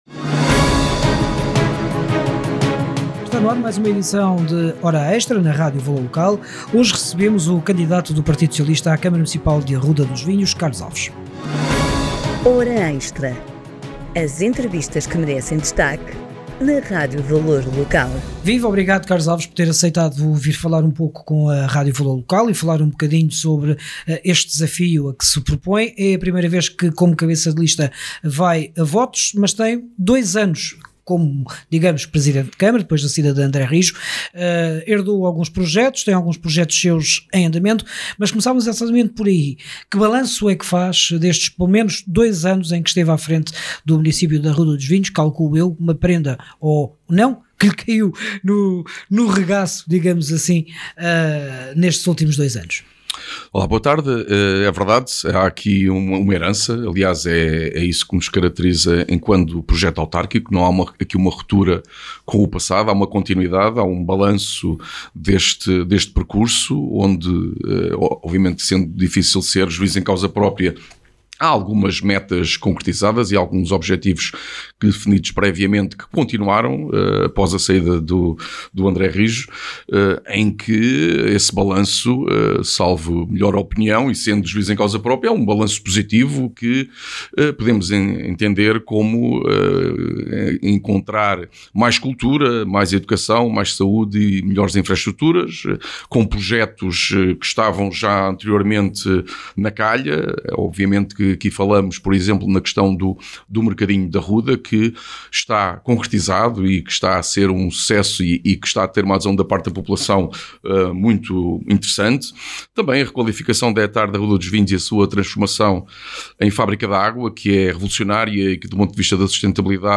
Autárquicas 2025 - Arruda dos Vinhos - Entrevista a Carlos Alves - PS - Jornal e Rádio Valor Local Regional - Grande Lisboa, Ribatejo e Oeste